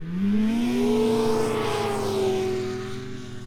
Snowmobile Description Form (PDF)
Subjective Noise Event Audio File - Run 3 (WAV)